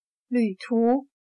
旅途/Lǚtú/Viaje, gira.